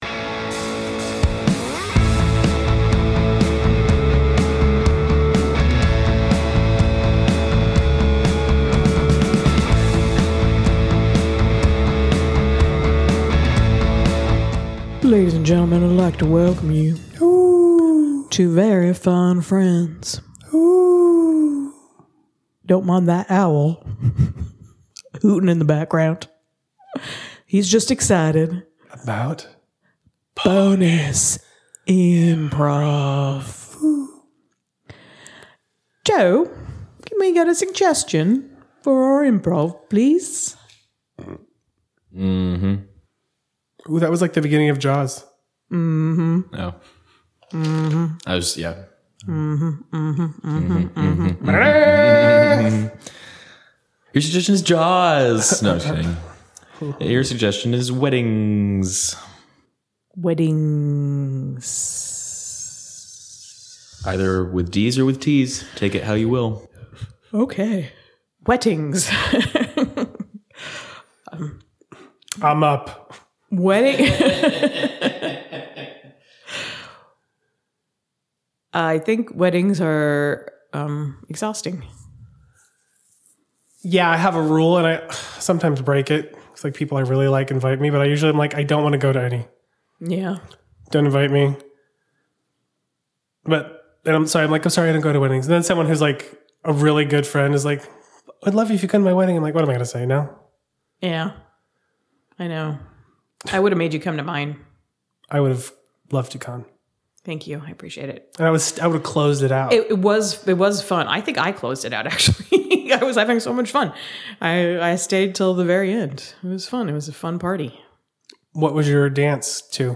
improv!